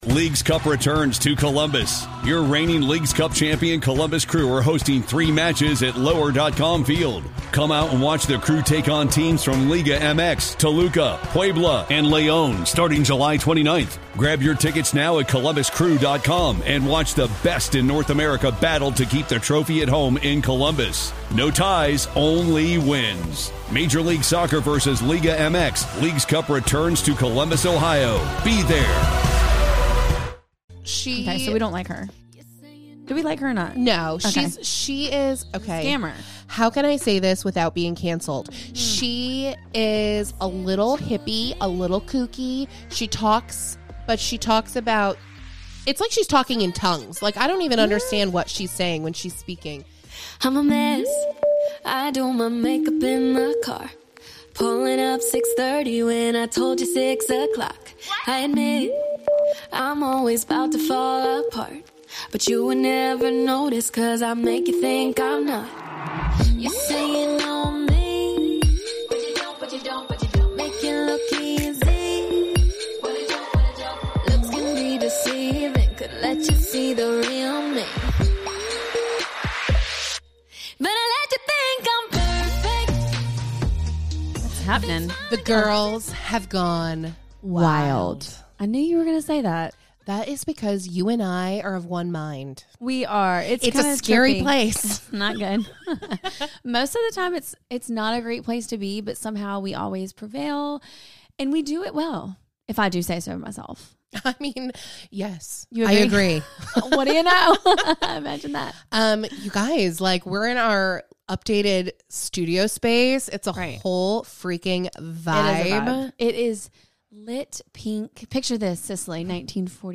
The girls heads are spinning over March MaNness, drool over the most basic Target collab to date and close the show with a dramatic read of some unfavorable reviews.